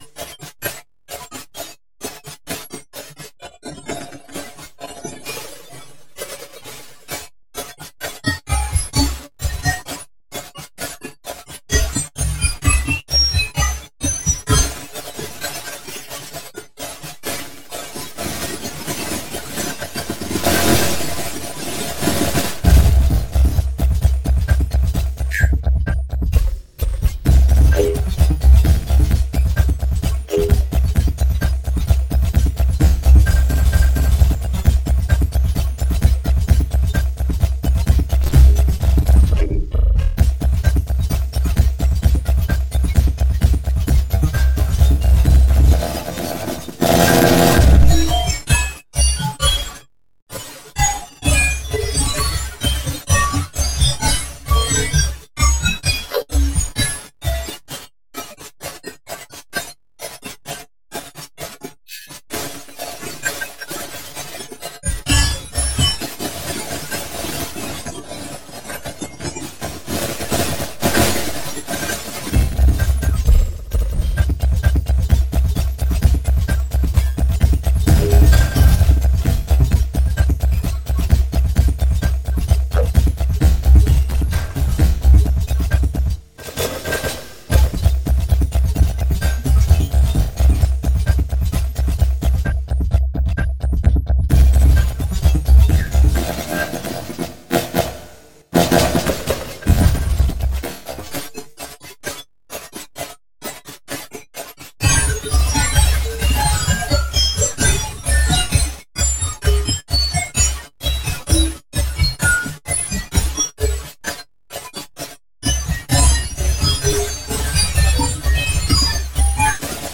This one uses a variety of audio effects I haven’t explored, including binshift, waveloss, real, and imag. I think all of those are based on spectral manipulation and I really love those sounds. Sometimes you can get that late 90’s low quality Napster mp3 download sound.
So I suppose this is techno, really messed up techno.
Oh and those times when everything stops and you hear a drone? Yeah my computer can’t keep up.